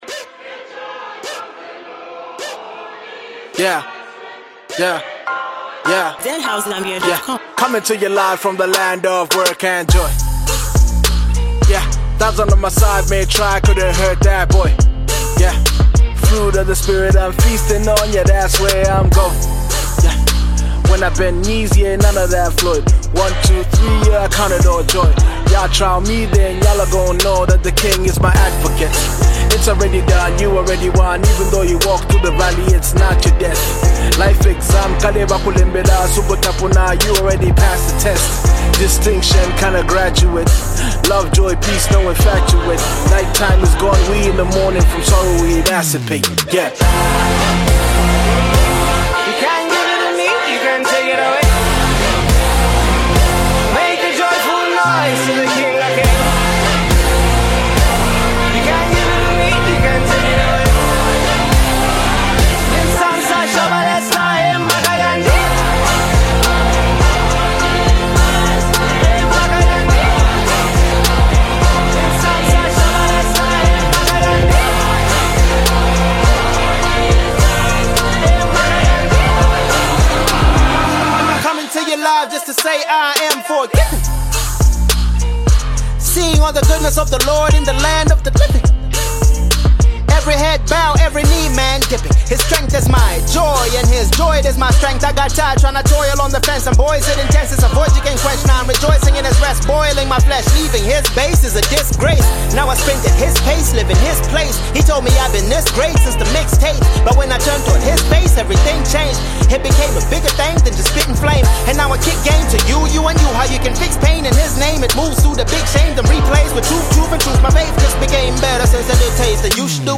a powerful and uplifting anthem that celebrates faith